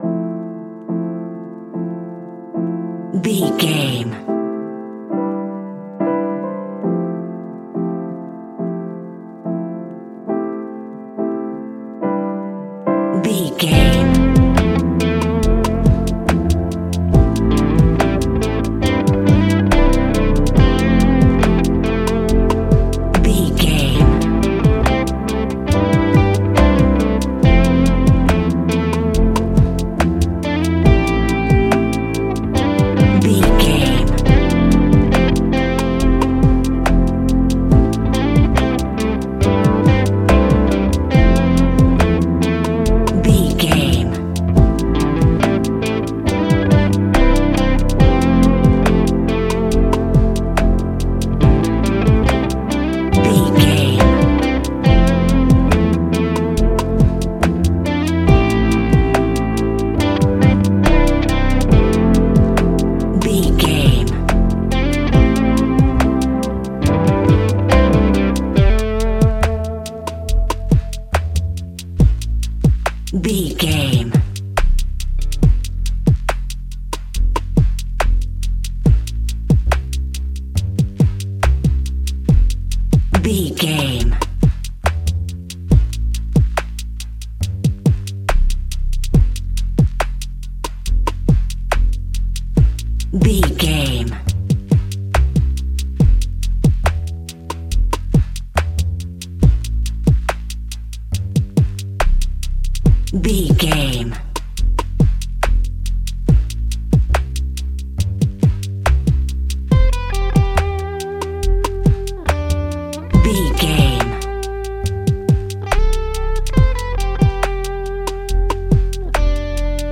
Ionian/Major
laid back
relaxed
Lounge
sparse
new age
chilled electronica
ambient
atmospheric
instrumentals